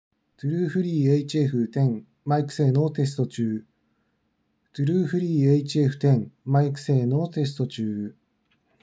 多少こもっているがノイズは無いマイク性能
少しだけ低音寄りで多少こもっていますがノイズがなく比較的クリアな音質のマイク性能。